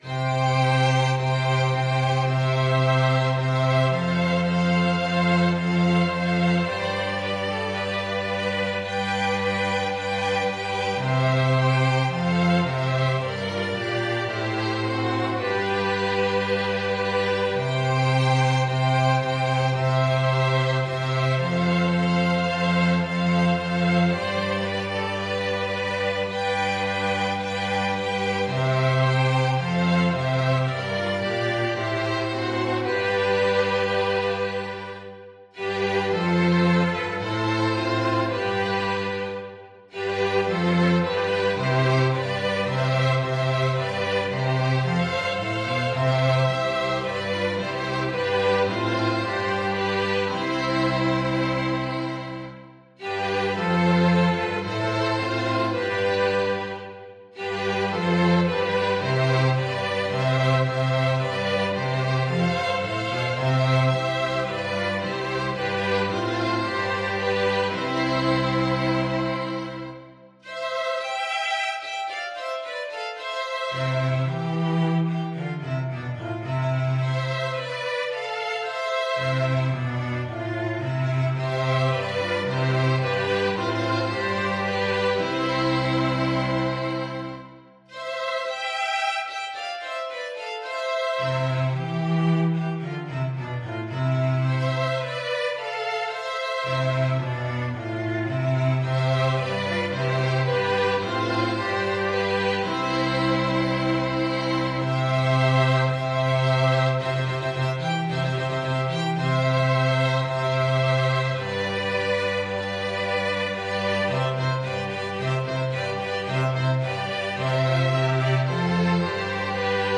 4/4 (View more 4/4 Music)
Classical (View more Classical String Quartet Music)